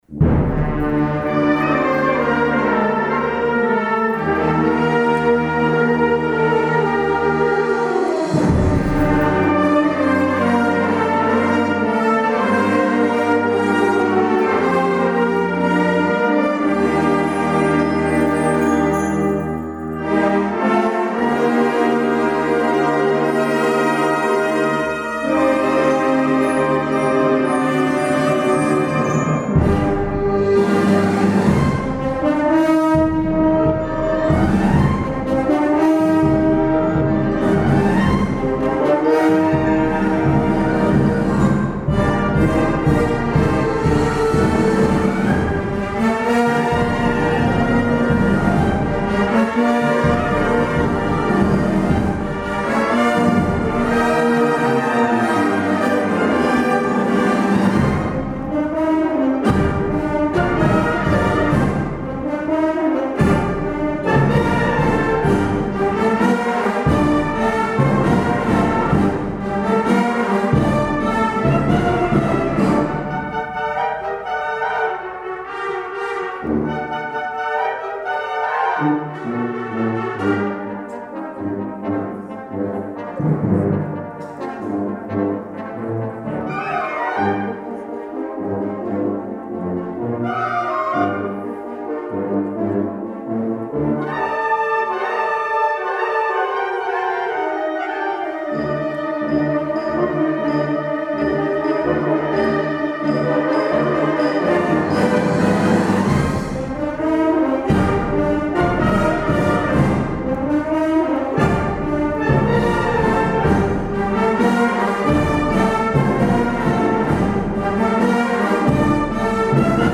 juntament amb Banda de Música de Llucmajor